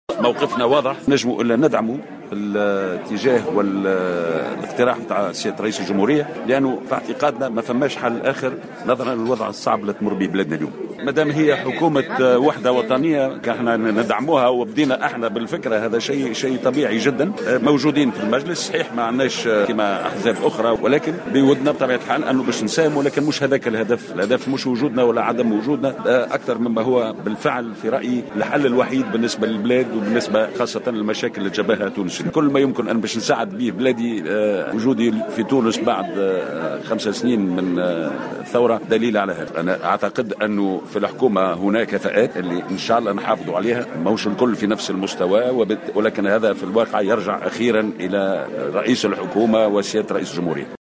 وابرز السبت في تصريح إعلامي على هامش اجتماع عام بشباب الحزب بالعاصمة، أن حزب المبادرة الوطنية الدستورية سبق له منذ سنتين على الأقل المطالبة بإطلاق برنامج إنقاذ وطني وتشكيل حكومة وحدة وطنية نظرا للوضع الصعب الذي تمر به البلاد.